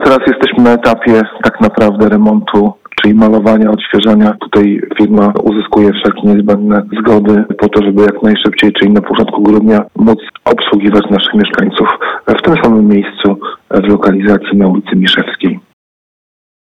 – dodaje wójt Zawadka.